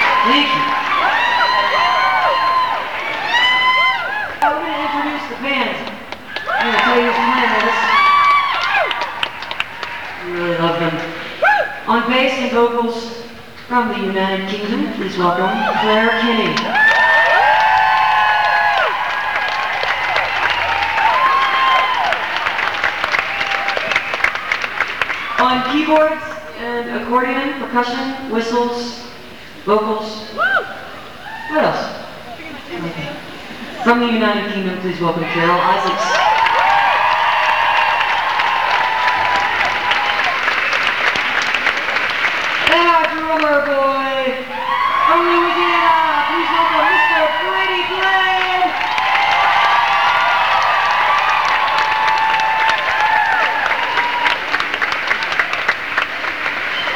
lifeblood: bootlegs: 2002: 2002-07-15: the palace - los angeles, california
07. band introductions (0:56)